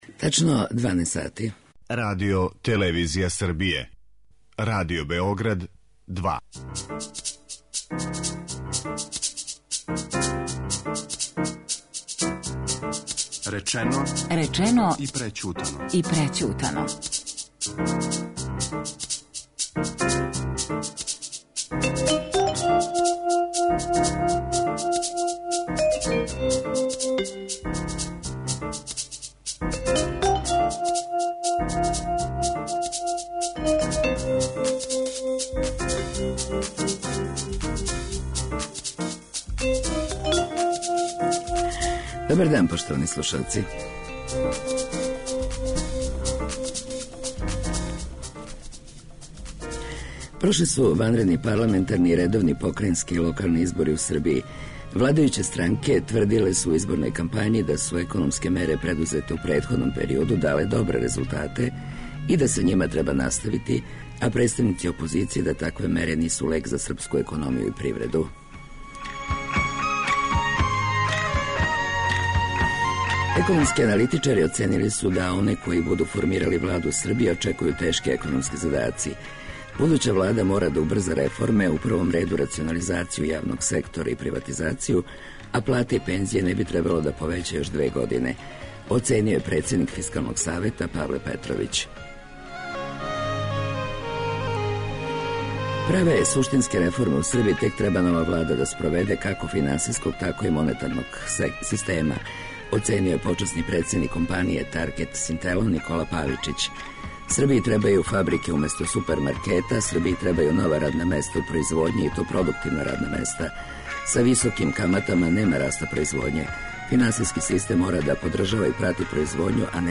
Шта чека нову владу, у емисији Речено и прећутано говоре економисти и привредници.